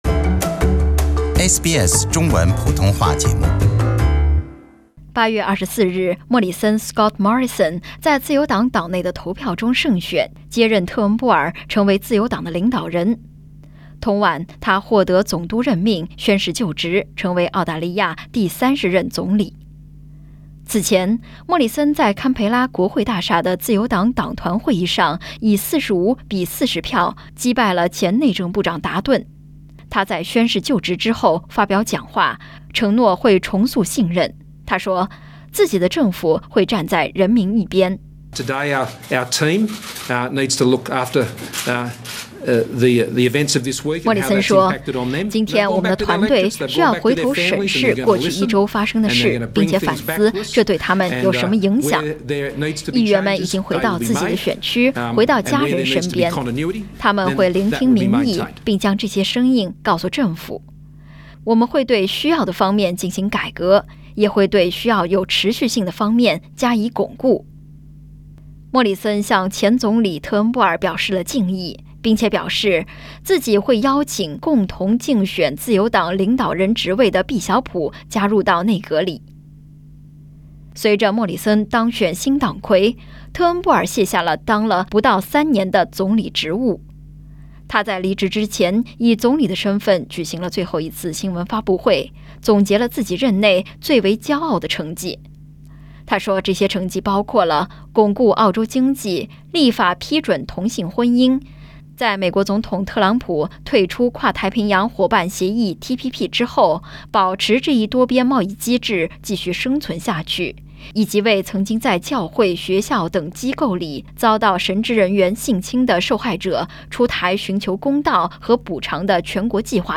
SBS在街头采访到的不少选民表示，他们对近年来澳大利亚领导人的频繁更替感到厌倦。